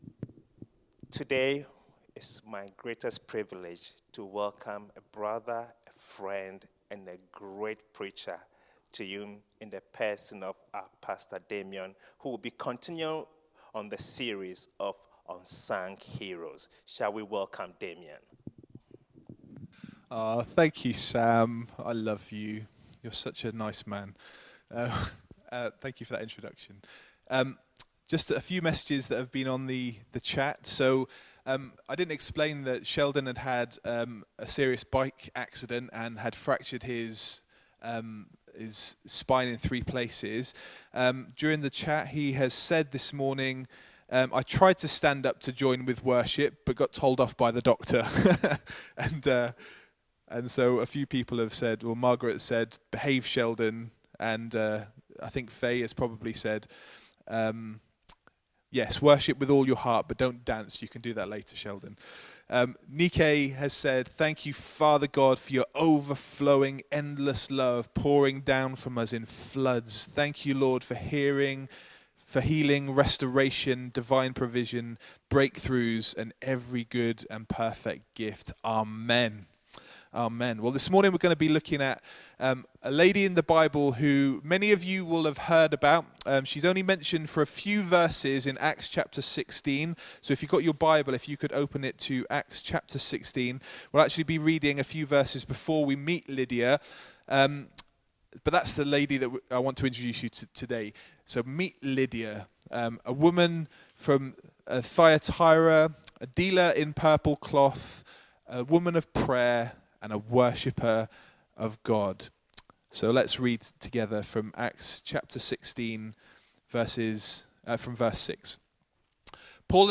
Aug 30, 2020 Unsung Heroes of the Bible…Meet Lydia MP3 SUBSCRIBE on iTunes(Podcast) Notes The final message in the series 'Unsung Heroes of the Bible' introduces us to Lydia, a business woman, a woman of prayer and worshipper of God.